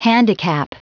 Prononciation du mot handicap en anglais (fichier audio)
Prononciation du mot : handicap